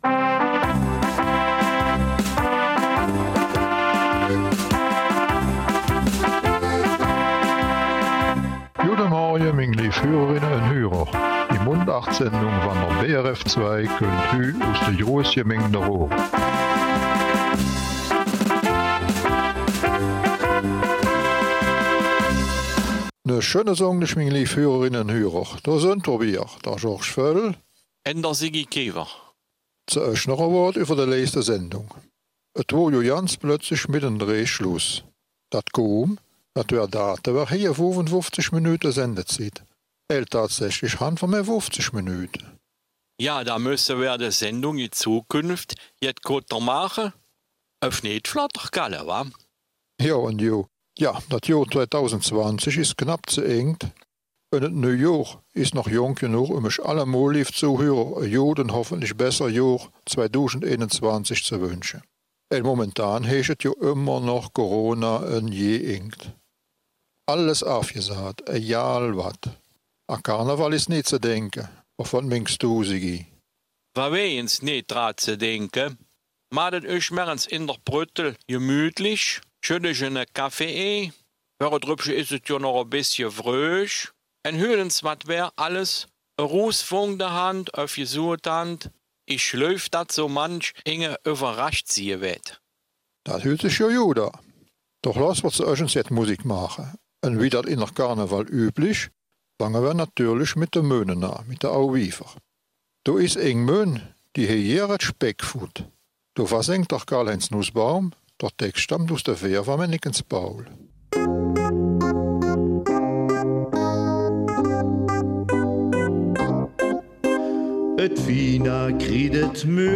Eine kurzweilige Chronologie des Raerener Karneval mit viel Musik zum Mitsingen, Schunkeln und Zuhören, ein Wiederhören mit einem in den späten 1960er Jahren bekannten Raerener Duo, eine Büttenrede aus dem gleichen Zeitraum sowie ein Gedicht aus den späten 1930er Jahren, das auch heute noch in unsere Zeit passt, aber zugegebenermaßen für jemanden, der des Raerener Platts nicht mächtig ist, etwas schwierig werden könnte.